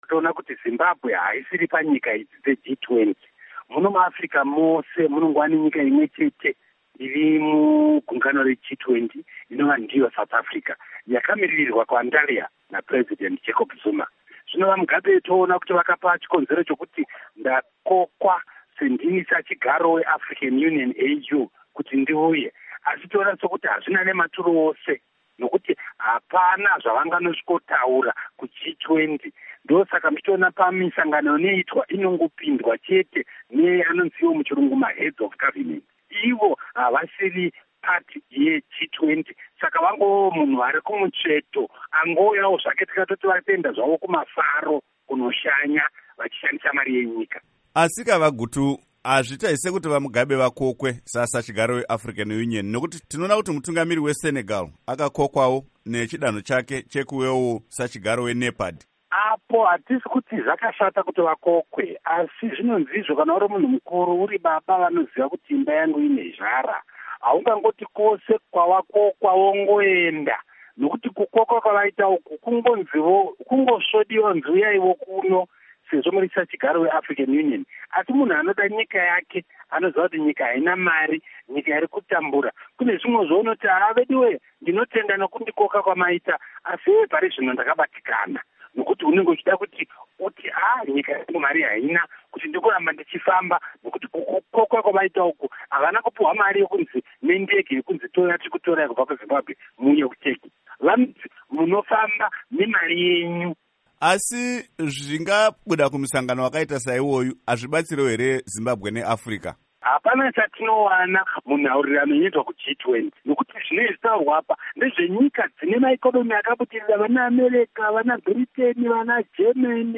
Hurukuro naVaObert Gutu